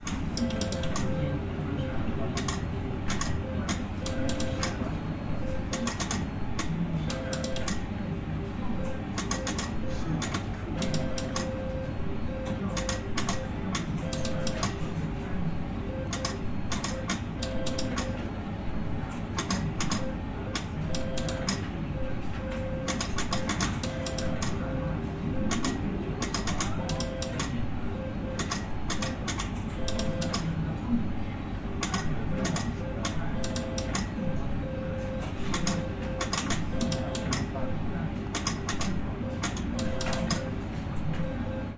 Descarga de Sonidos mp3 Gratis: tragamonedas 3.
tragaperras-tragamonedas 4.mp3